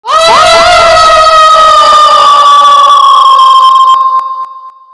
Download Scream sound effect for free.
Scream